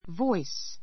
voice 中 A2 vɔ́is ヴォ イ ス 名詞 ❶ 声 in a loud [small] voice in a loud [small] voice 大声[小声]で Can you hear my voice?—No, your voice is too small.